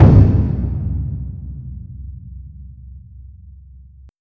HitSounds / Gorgeous Taiko
dong.wav